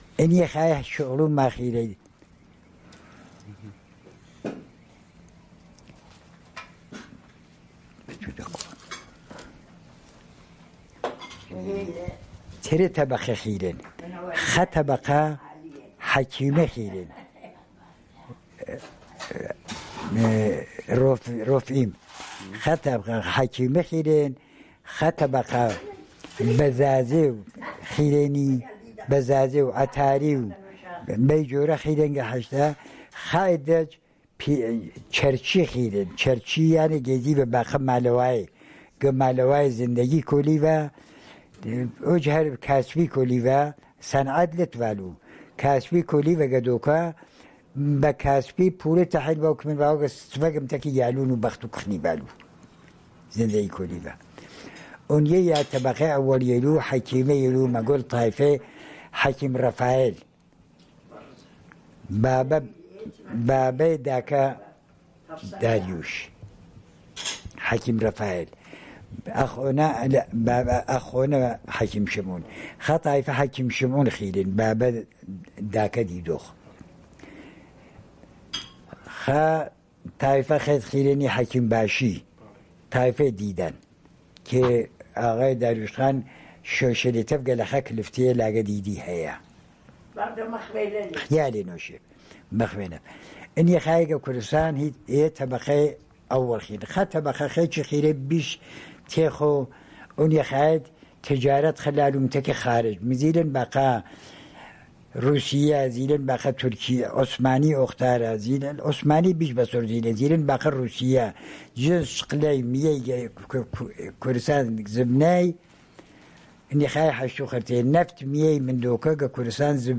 Sanandaj, Jewish: Professions of the Jews